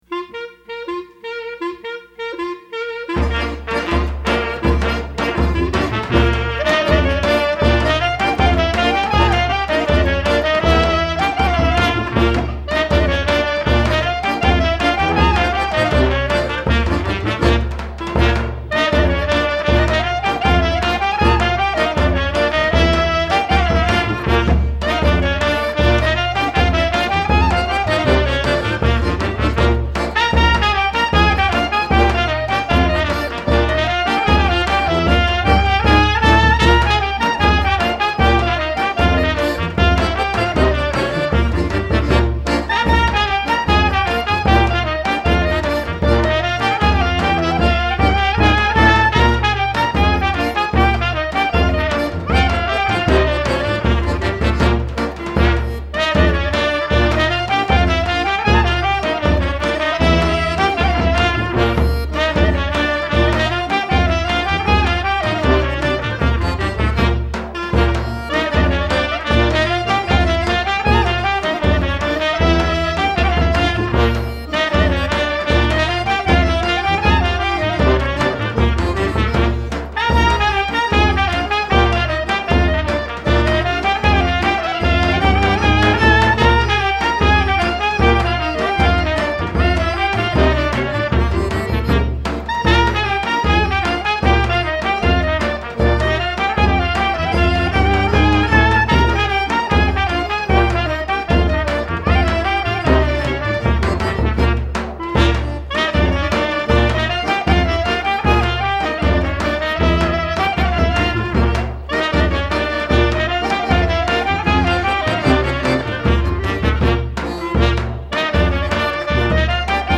Eastern European wedding music